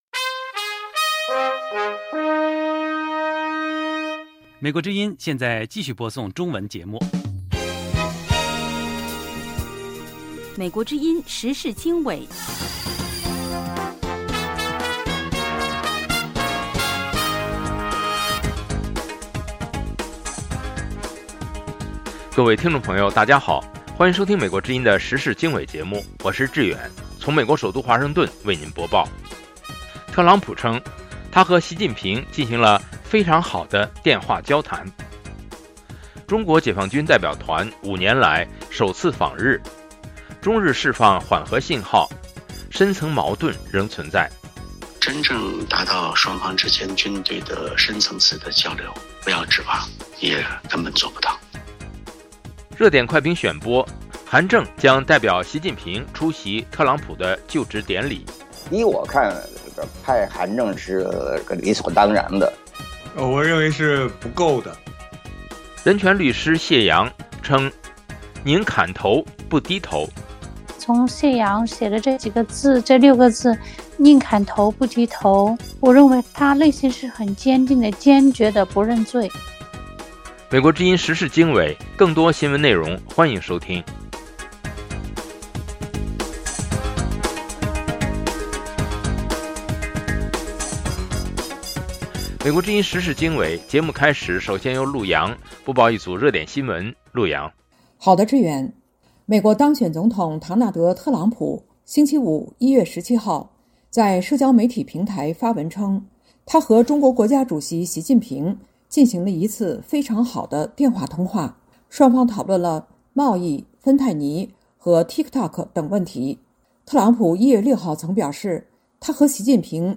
美国之音中文广播《时事经纬》重点报道美国、世界和中国、香港、台湾的新闻大事，内容包括美国之音驻世界各地记者的报道，其中有中文部记者和特约记者的采访报道，背景报道、世界报章杂志文章介绍以及新闻评论等等。